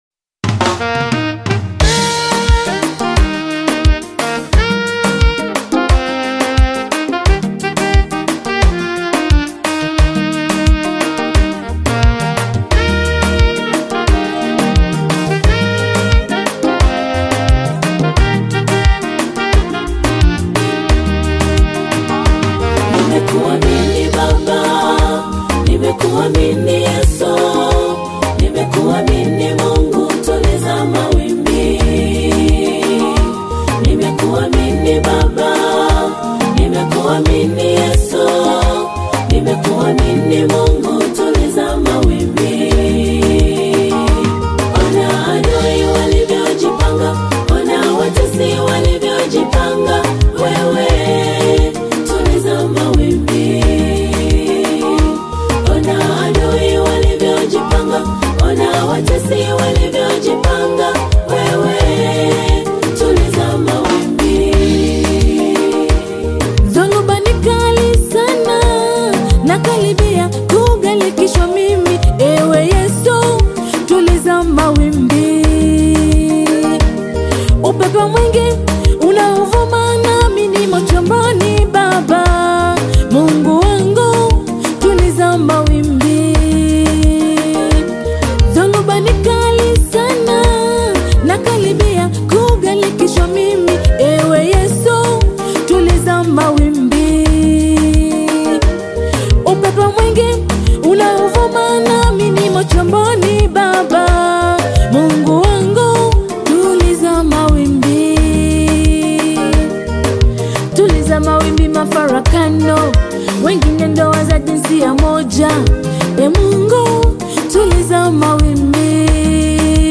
Download Gospel Song